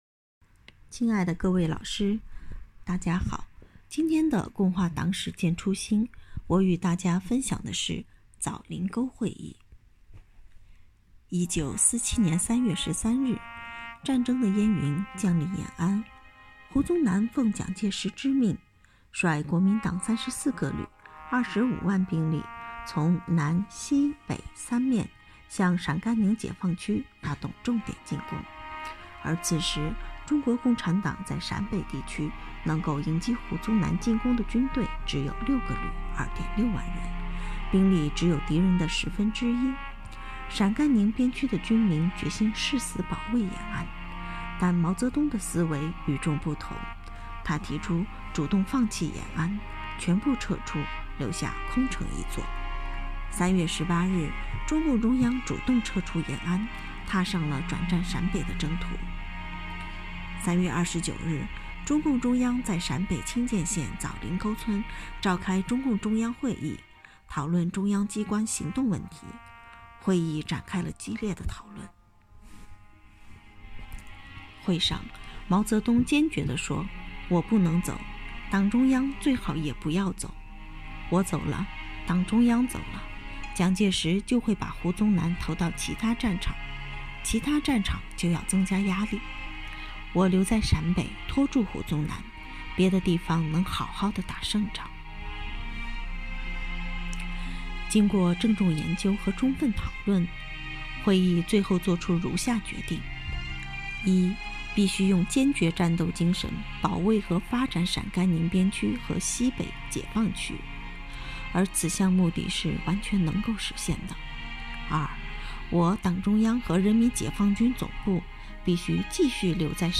序章：为了加强组织建设，提高党员教育成效，落实党员“五个一”活动要求，科技处党支部从3月23日起开展了“共话党史践初心——党史人人讲”系列活动，党员每天讲一个党史故事，辅以相关学习内容的延伸阅读，以小见大，不断巩固“不忘初心 牢记使命”主题教育成果，以昂扬的精神面貌，庆祝中国共产党成立100周年！！！